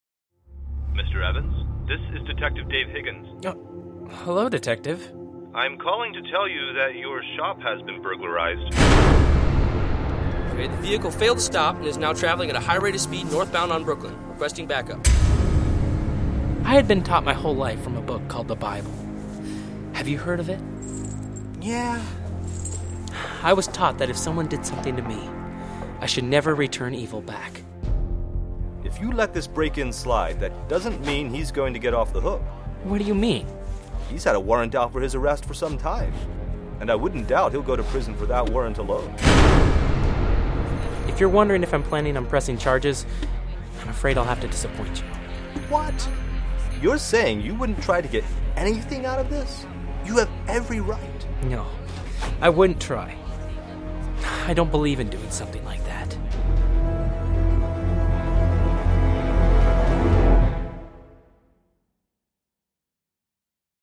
The album seeks to highlight and encourage Anabaptist values through audio drama similar to Adventures in Odyssey.
A-Lawyers-Nightmare-Audio-Drama-Trailer.mp3